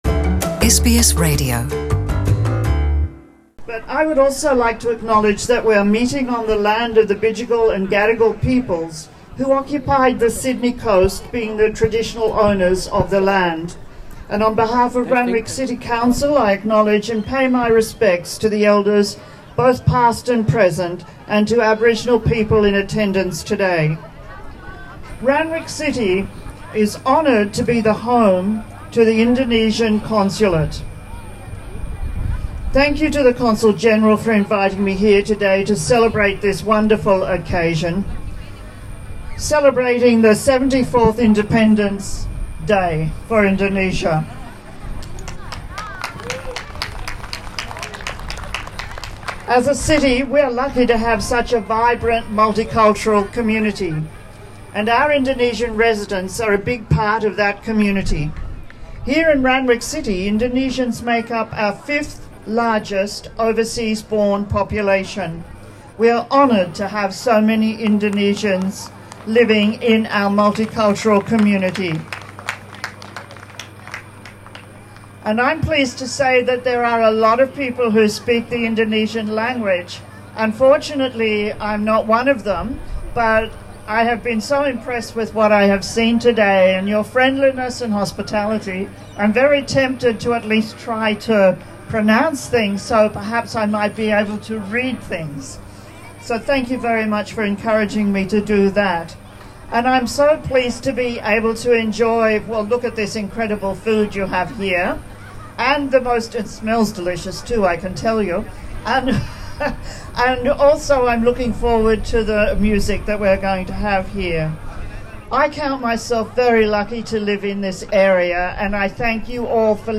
Pidato wali kota Randwick, Kathy Neilson, untuk HUT Indonesia ke-74.